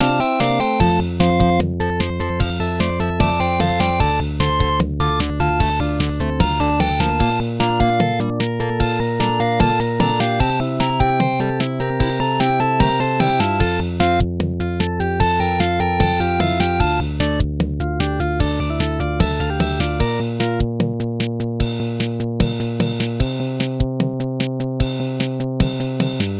and there are no lyrics cause its a video gamey one, specifically an instrumental one. its actually quite late right now so i think im gonna go to sleep. but id feel bad if i didnt just leave the wav links for convenience because who would wanna do the whole edit the link thingy. so here you go.